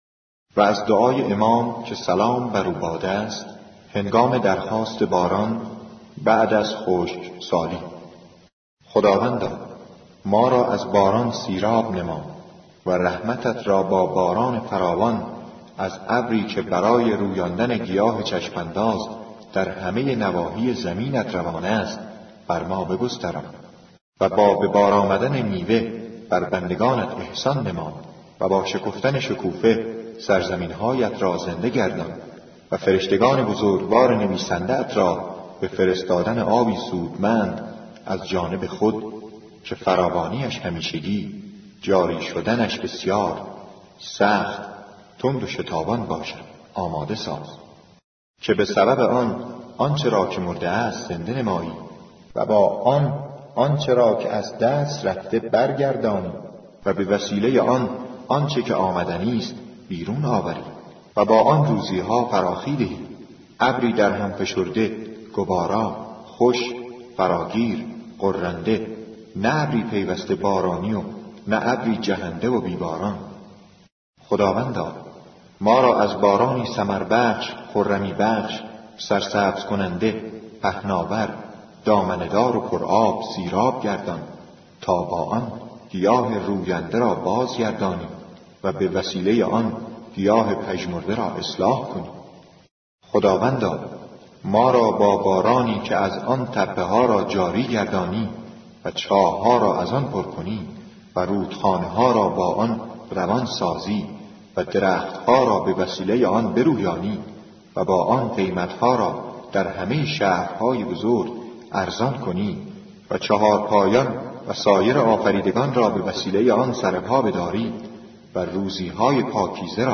• موسیقی ملل